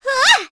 Pansirone-Vox_Attack3.wav